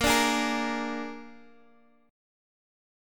Bb7 Chord
Listen to Bb7 strummed